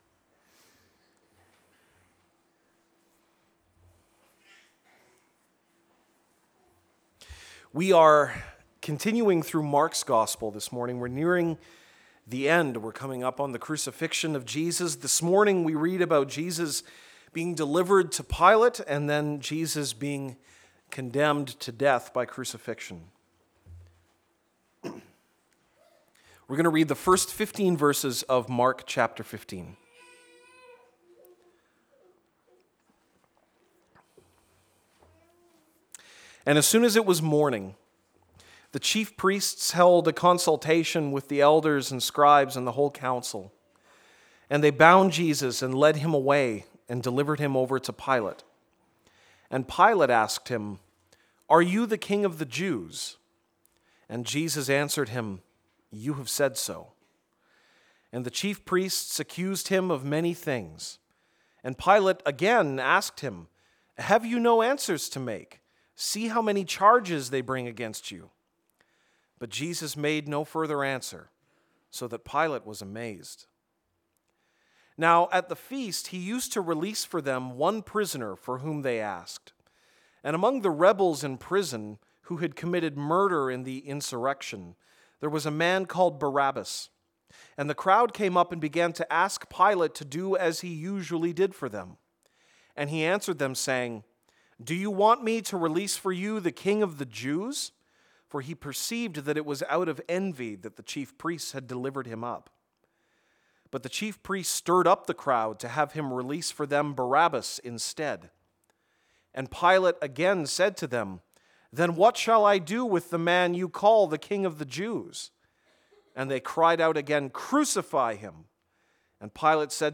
April 30, 2017 (Sunday Morning)